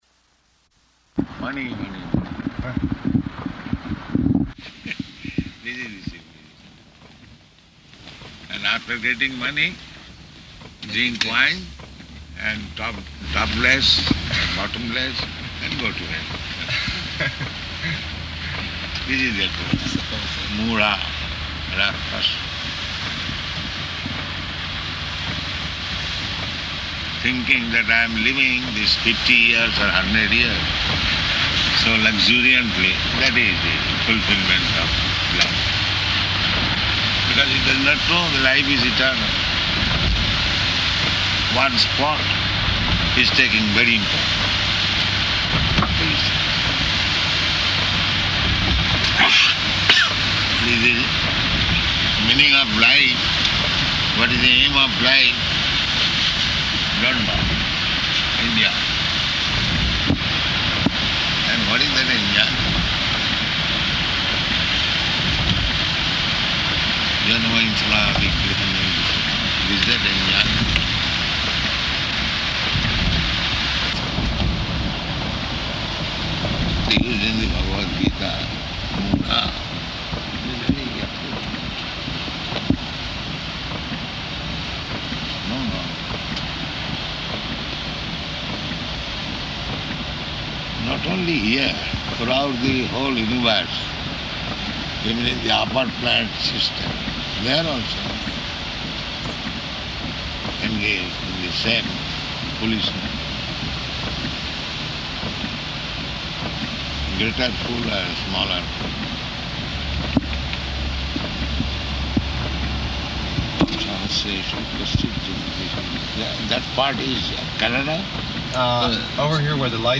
Car Conversation
Car Conversation --:-- --:-- Type: Caitanya-caritamrta Dated: August 3rd 1975 Location: Detroit Audio file: 750803CC.DET.mp3 Prabhupāda: Money means...